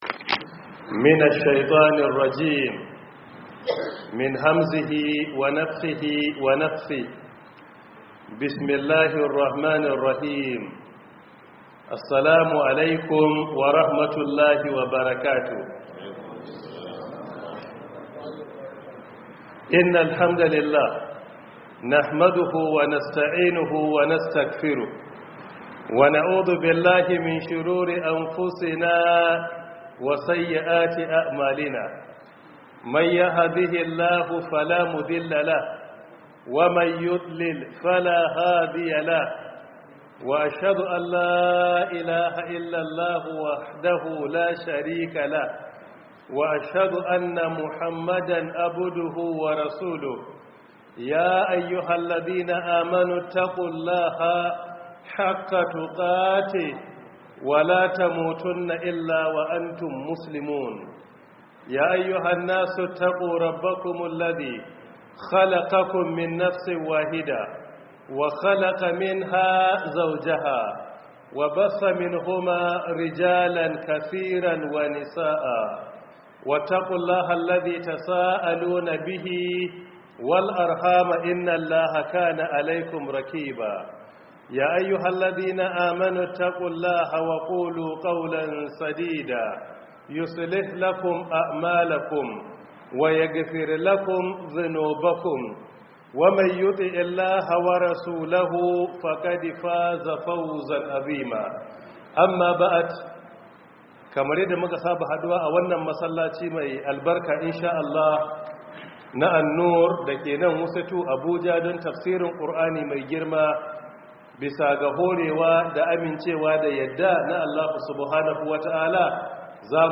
Audio lecture by Professor Isa Ali Ibrahim Pantami — 1447/2026 Ramadan Tafsir